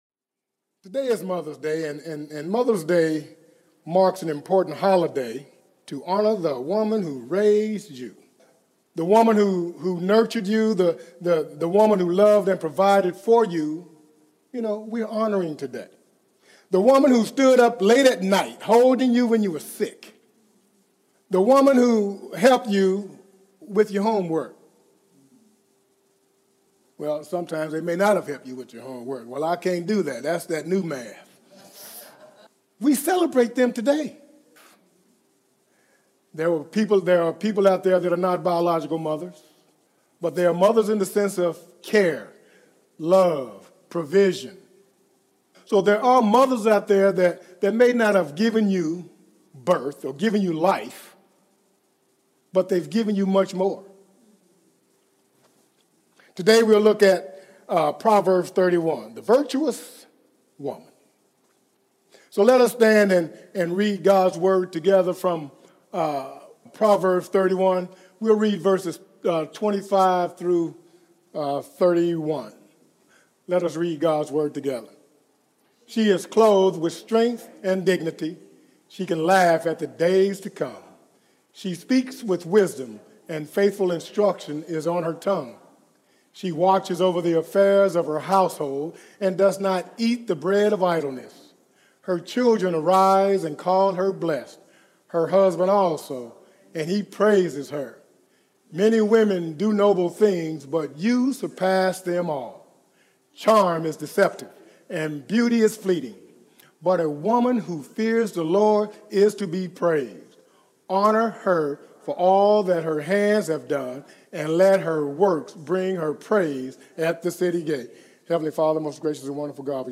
Sermons & Messages